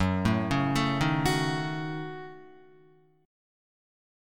F#m6 chord